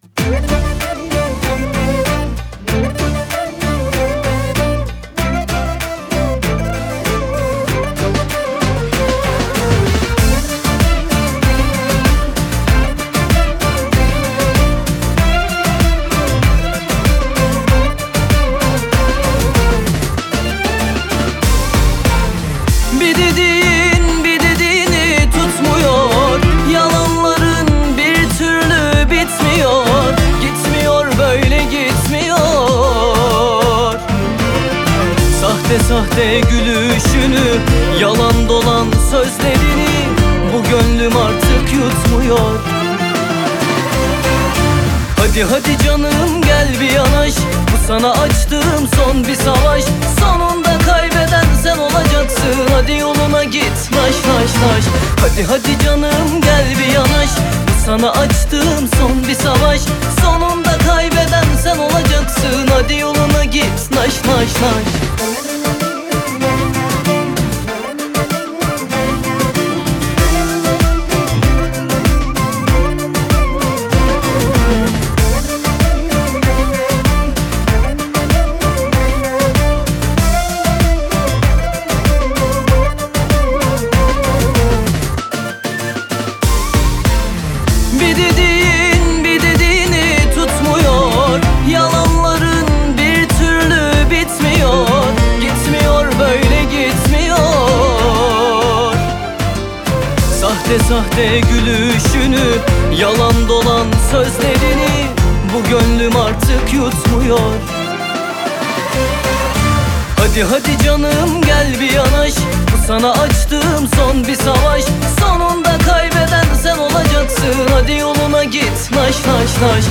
آهنگ محلی شاد ترکی
Happy Local Song of Turki